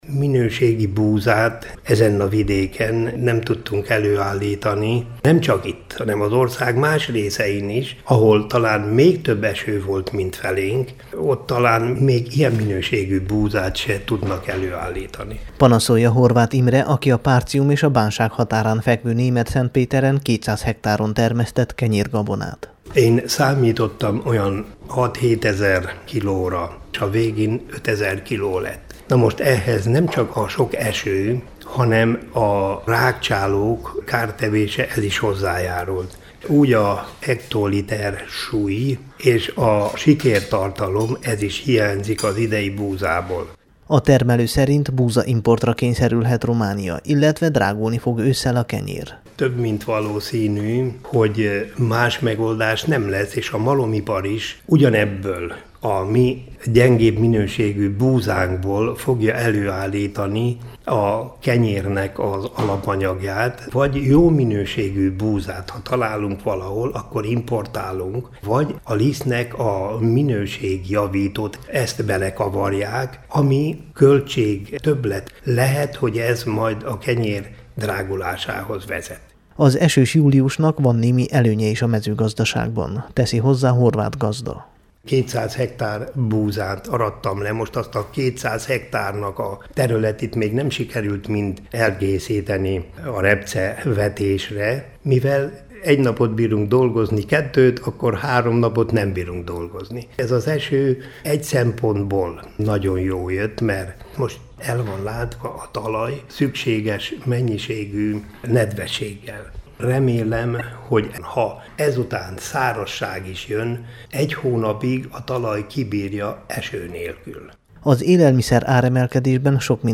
összeállítása a Temesvári Rádió számára készült.